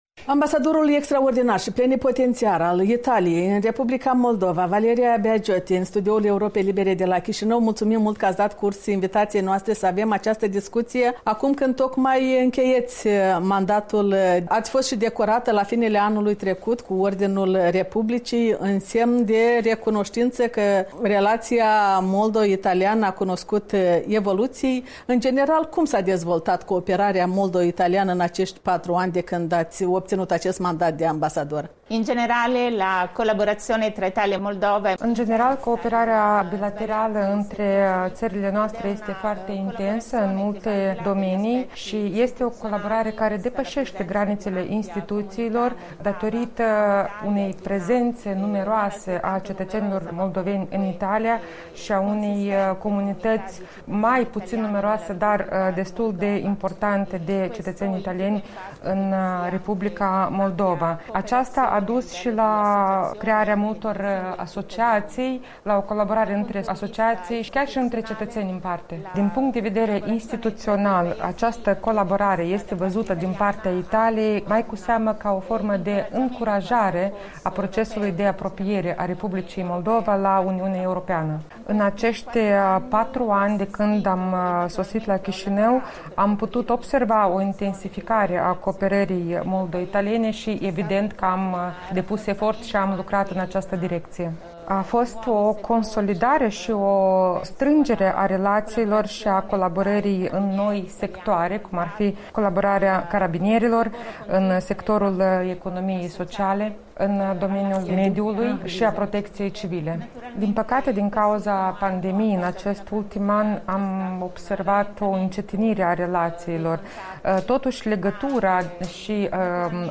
Interviu cu ambasadoarea Republicii Italiene în R. Moldova.